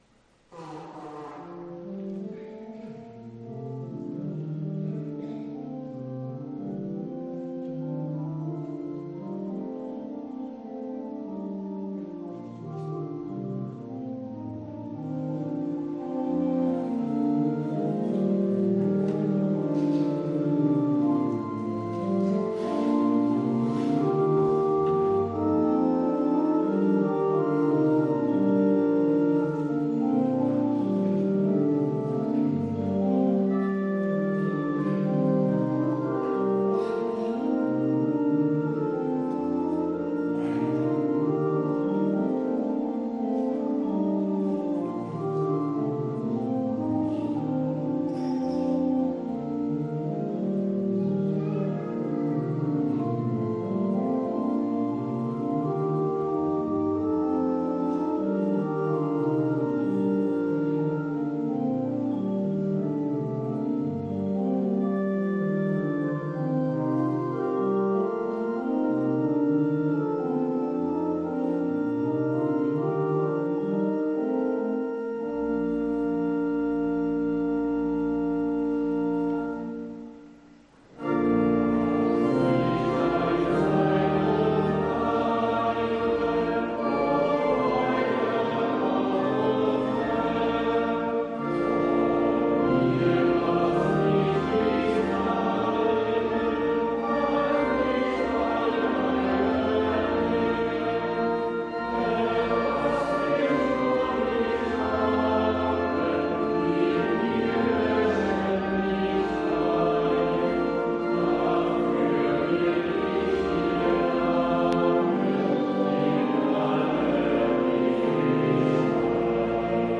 Gottesdienst am 03.10.2021